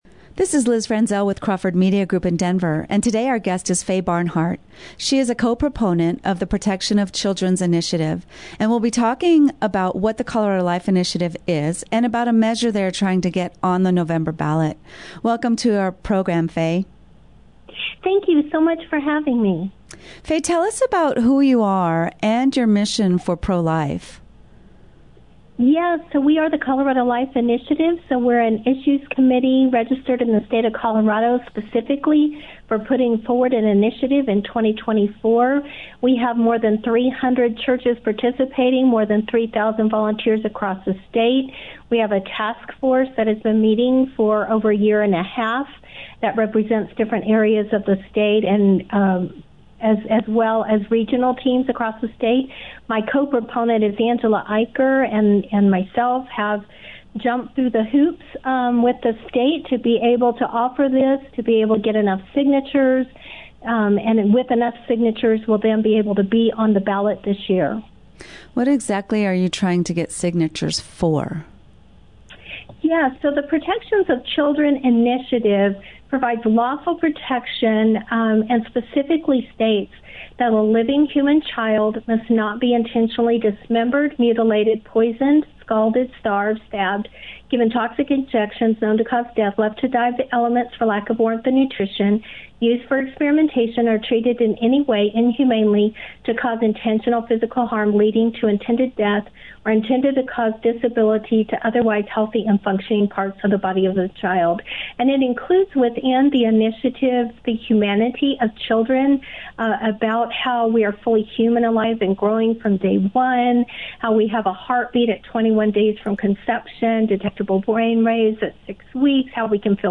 Radio Interview regarding the Initiative